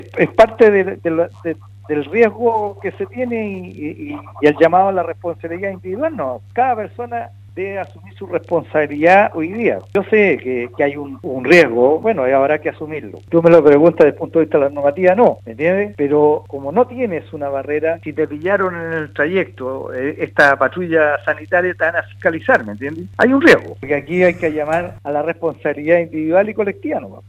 En conversación con Radio Sago, el alcalde de la comuna costera, Bernardo Candia, confirmó que tras una reunión con la Seremi (s) de Salud Los Lagos, Marcela Cárdenas  se plantearon diversos argumentos que lograron concretar que la medida comience a partir de este lunes 30 de noviembre.